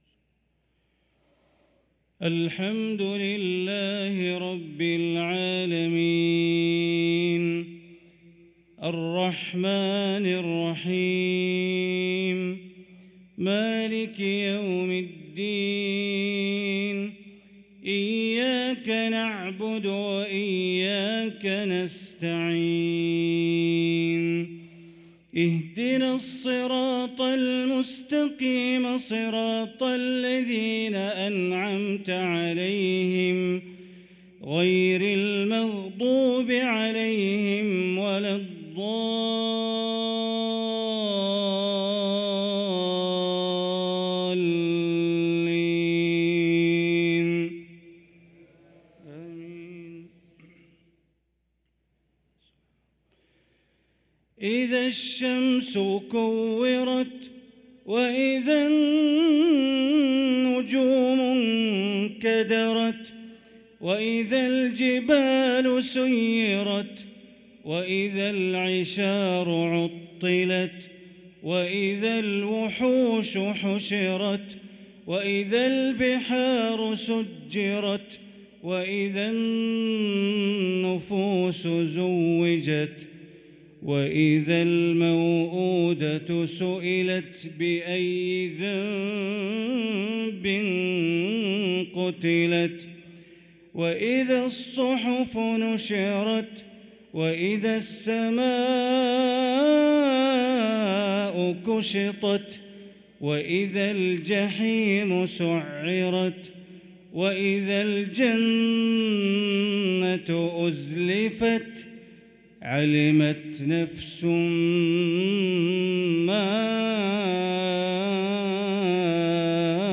صلاة العشاء للقارئ بندر بليلة 13 صفر 1445 هـ
تِلَاوَات الْحَرَمَيْن .